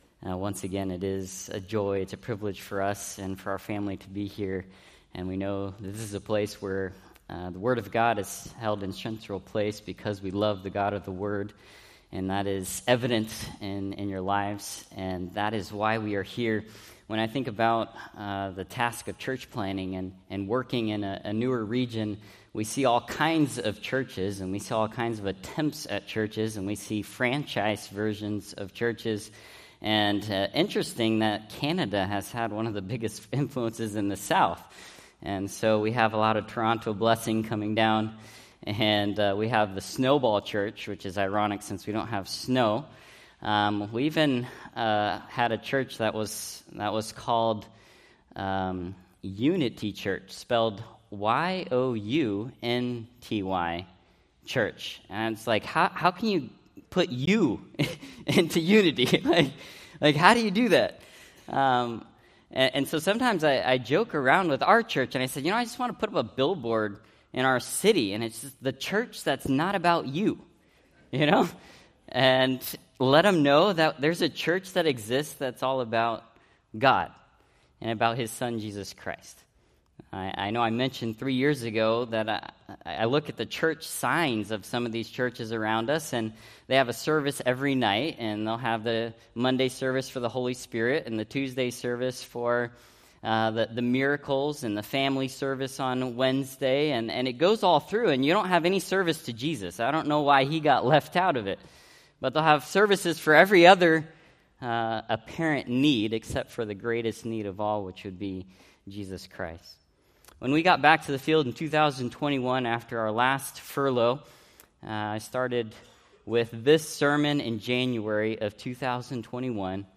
Preached April 6, 2025 from Psalm 90